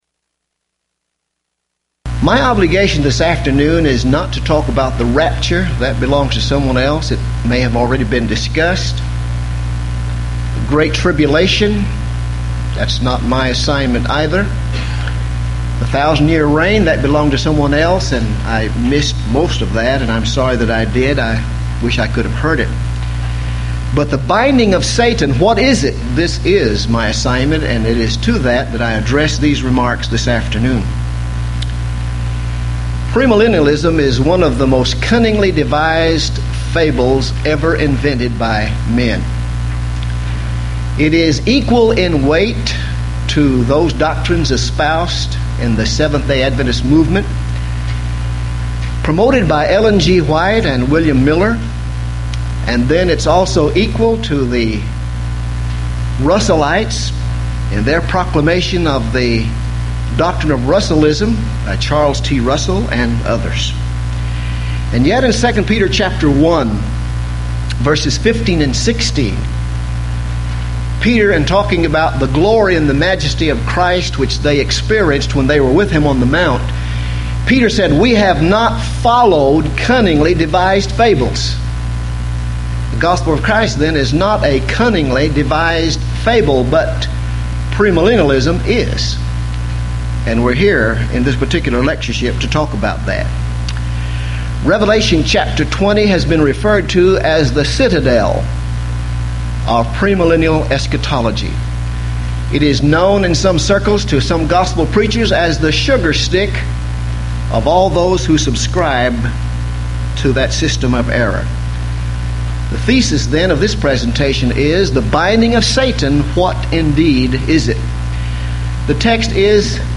Series: Houston College of the Bible Lectures Event: 1997 HCB Lectures Theme/Title: Premillennialism